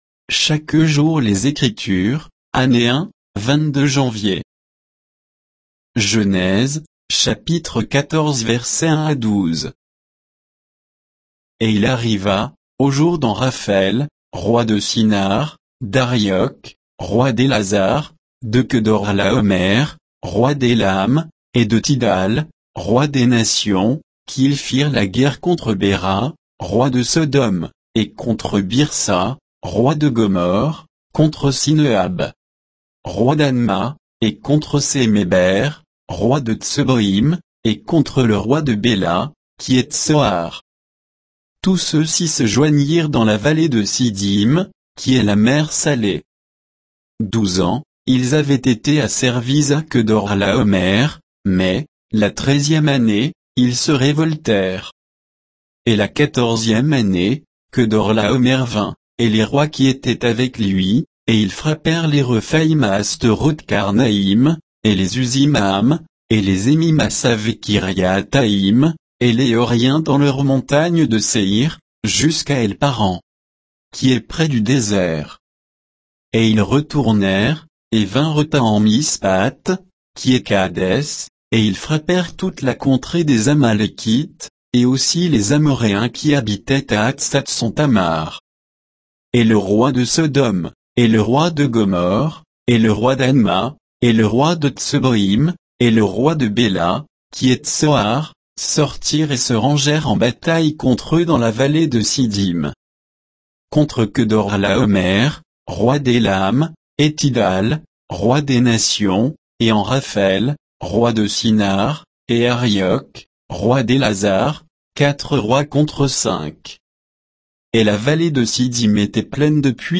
Méditation quoditienne de Chaque jour les Écritures sur Genèse 14